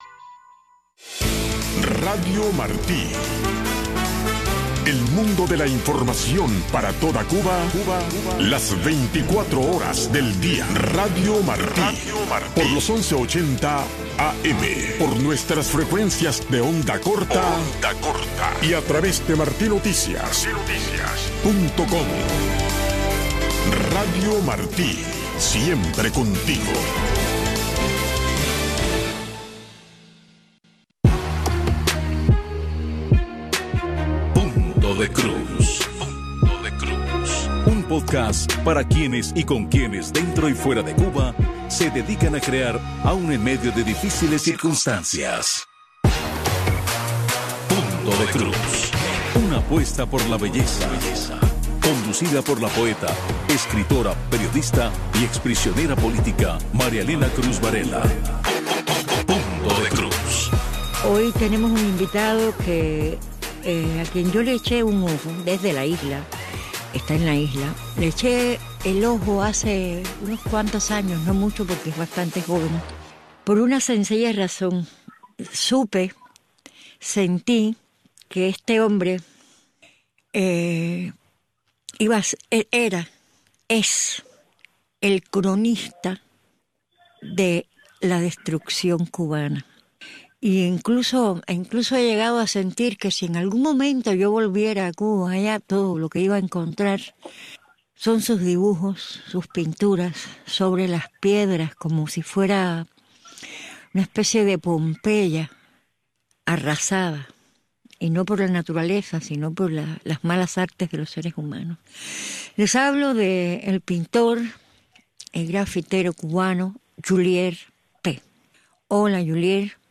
Las voces que testimonian la vida del cubano de a pie.